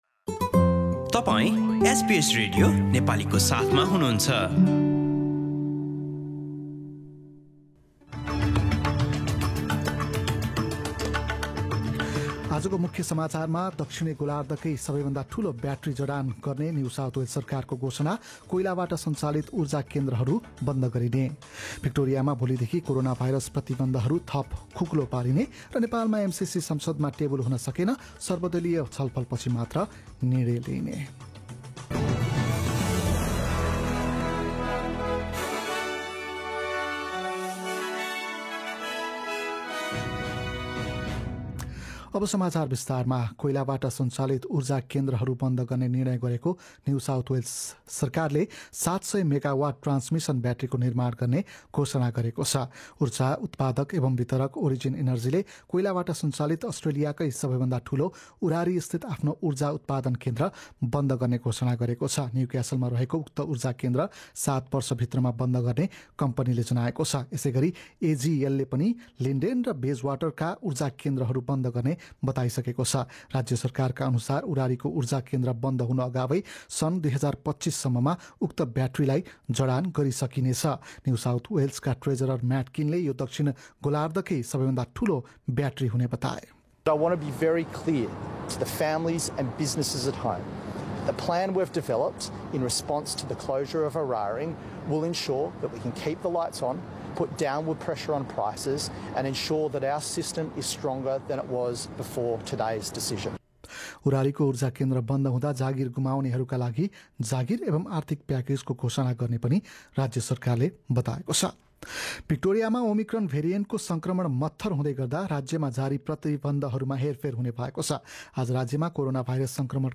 एसबीएस नेपाली अस्ट्रेलिया समाचार: बिहीवार १७ फेब्रुअरी २०२२